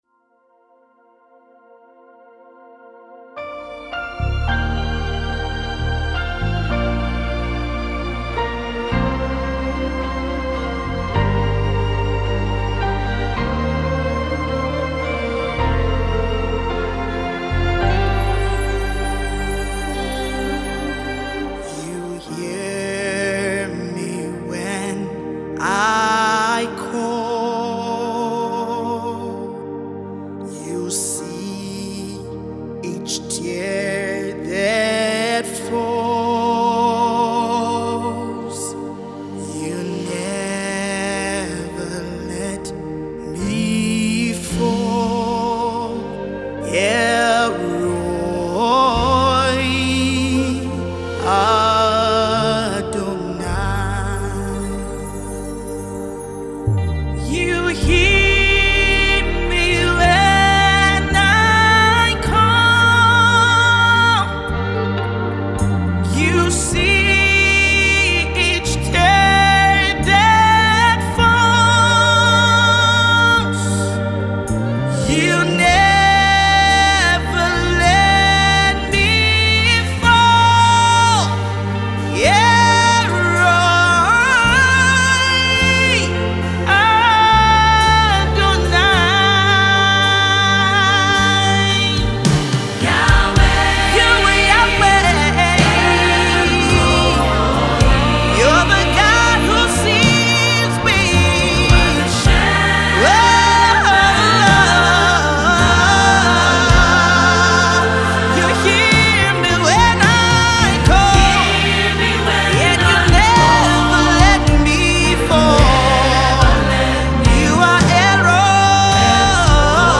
uplifting music